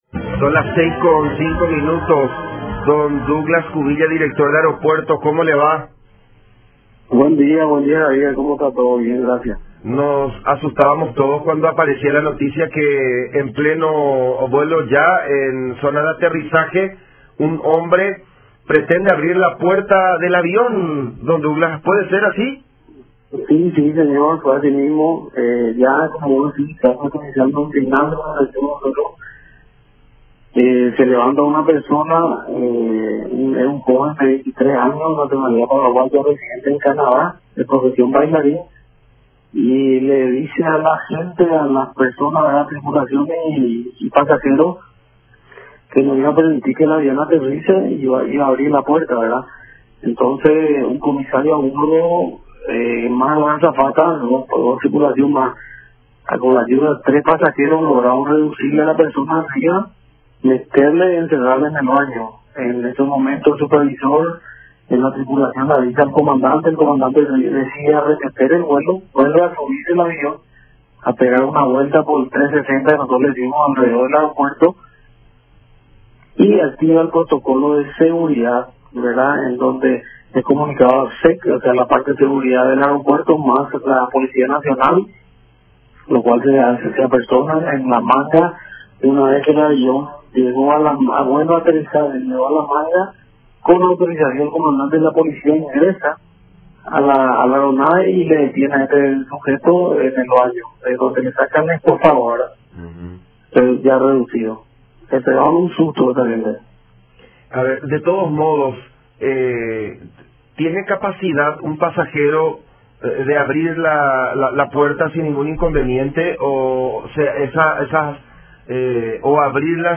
“Él viajaba solo, pero era muy llamativo el modo de responder, decía que quería ‘liberar a todos'”, detalló Cubilla en diálogo con La Unión.
03-Douglas-Cubilla-Director-de-Aerpuertos-sobre-pasajero-que-quiso-abrir-la-puerta-de-un-avión-en-pleno-vuelo.mp3